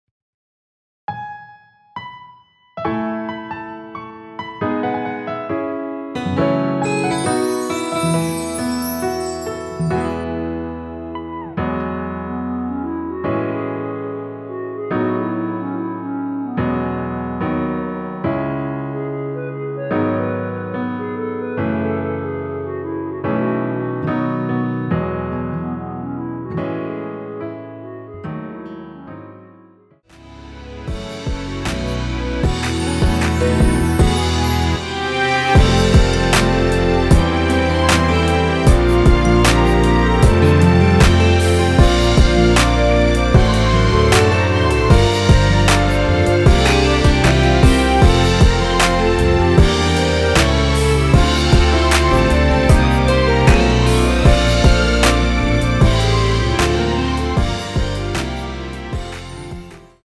원키에서(-3)내린 멜로디 포함된 MR입니다.
앞부분30초, 뒷부분30초씩 편집해서 올려 드리고 있습니다.